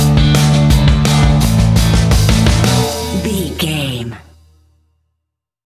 Mixolydian
fun
energetic
uplifting
instrumentals
upbeat
groovy
guitars
bass
drums
piano
organ